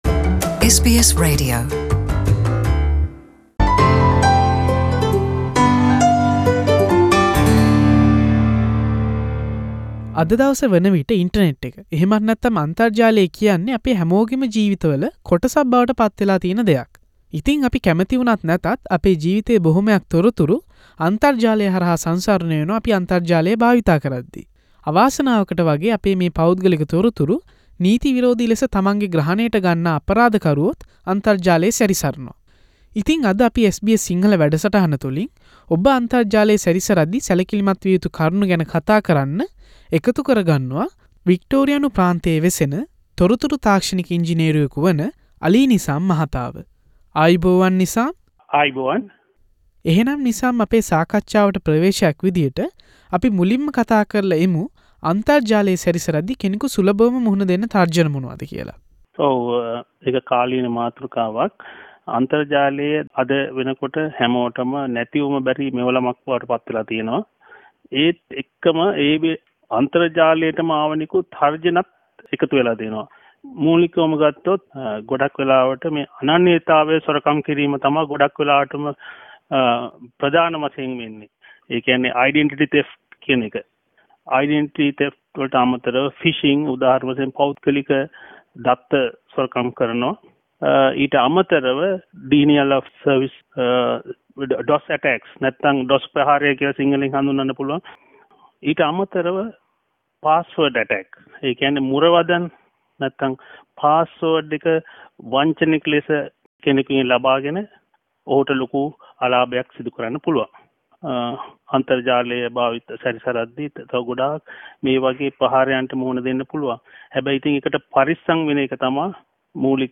SBS සිංහල වැඩසටහන සිදු කල කතාබහක්.